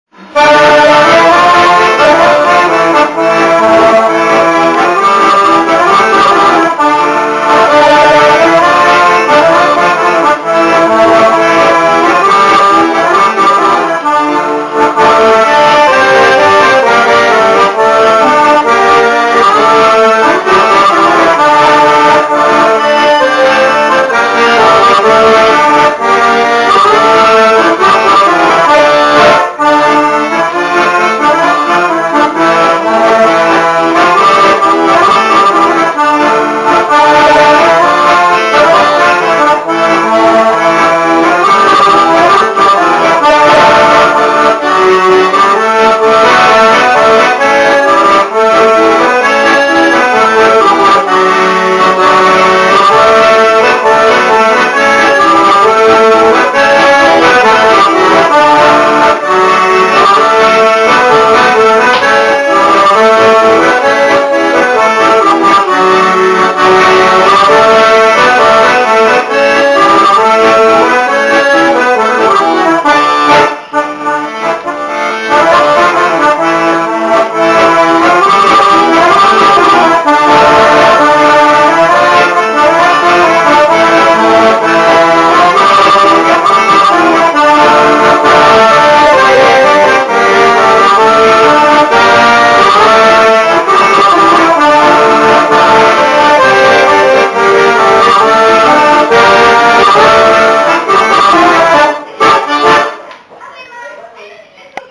POLKA RUSA TRADICIONAL